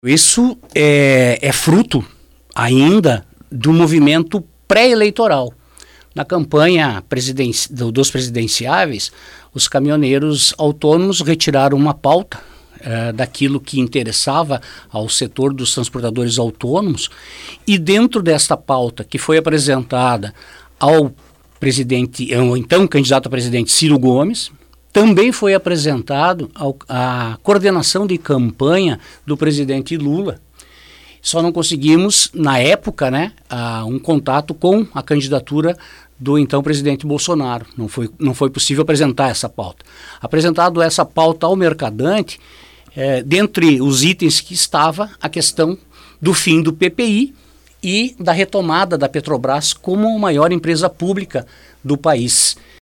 discorreu sobre alguns aspectos da atividade durante entrevista ao Fatorama. Sobre acidentes, destacou que há um conjunto de fatores responsáveis, citando cansaço diário, má remuneração e as condições das estradas.